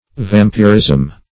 Vampirism \Vam"pir*ism\, n. [Cf. F. vampirisme.]